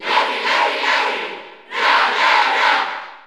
Bowser Jr. (SSBU) Category: Crowd cheers (SSBU) You cannot overwrite this file.
Larry_Cheer_Spanish_NTSC_SSB4_SSBU.ogg